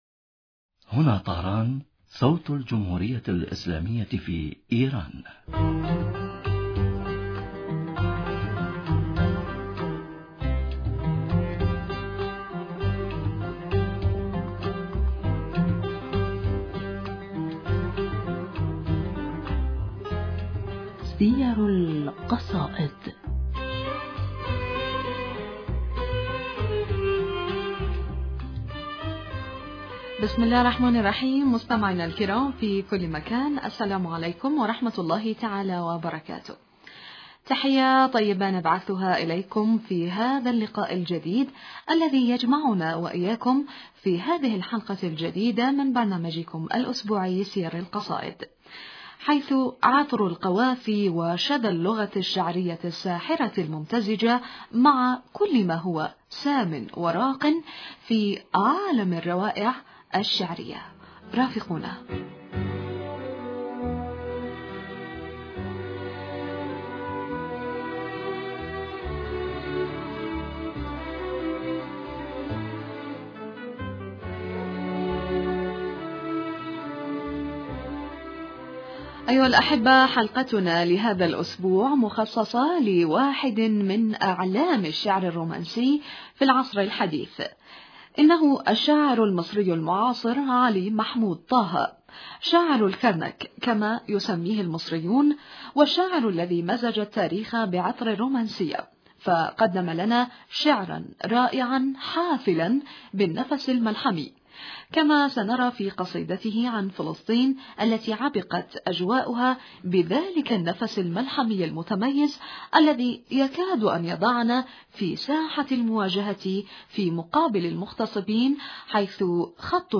معنا في الأستوديو